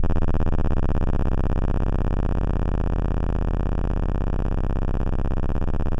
C1_trance_lead_1.wav